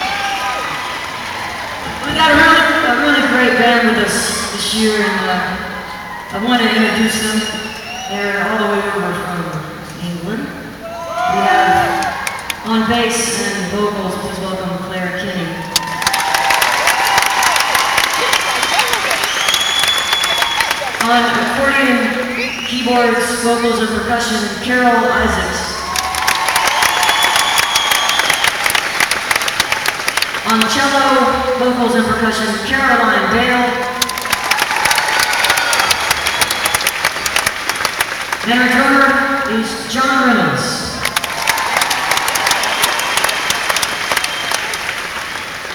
lifeblood: bootlegs: 1999-08-22: molson amphitheater - toronto, canada (lilith fair)
(band show)
04. talking with the crowd (0:45)